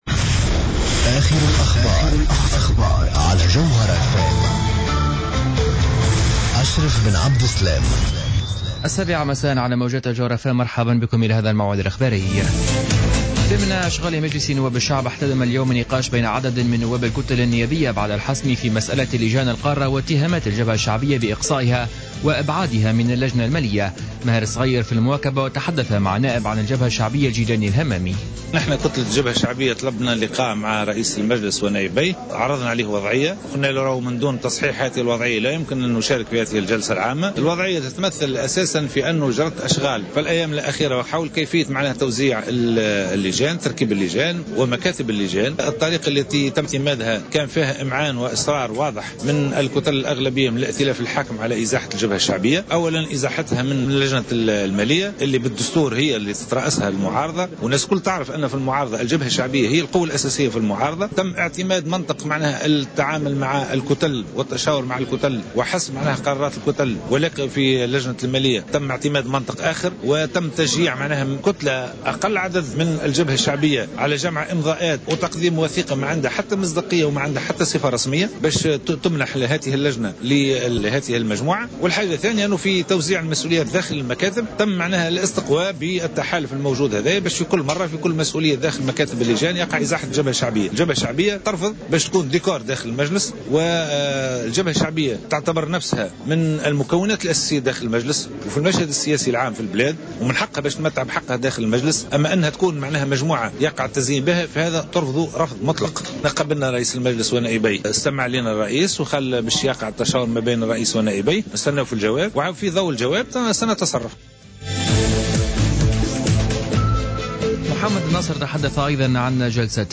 نشرة أخبار السابعة مساء ليوم الجمعة 20 فيفري 2015